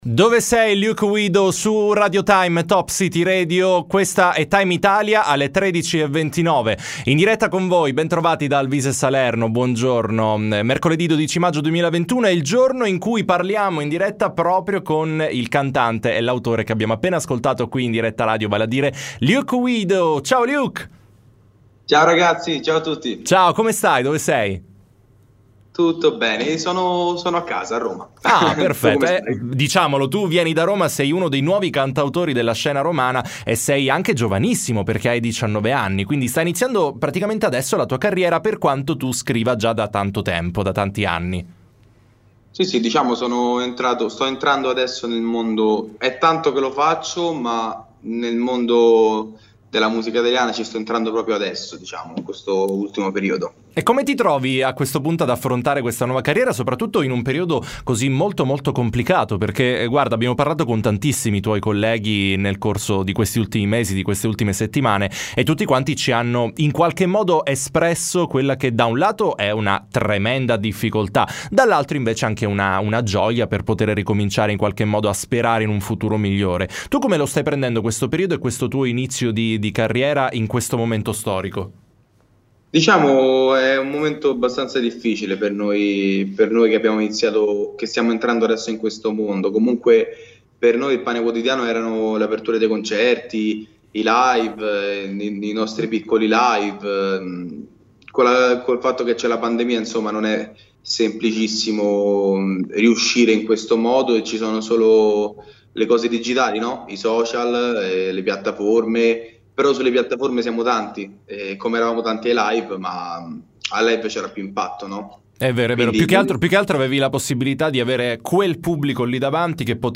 T.I. Intervista